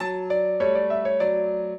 minuet11-5.wav